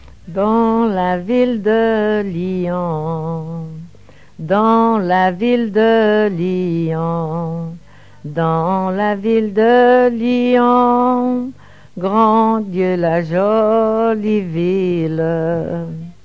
Genre laisse
collecte du répertoire de chansons
Pièce musicale inédite